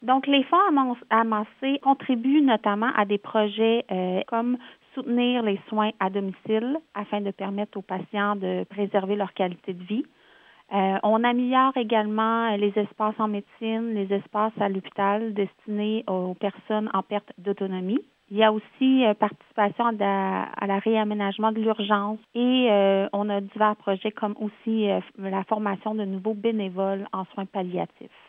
en entrevue avec le service de nouvelles de M105.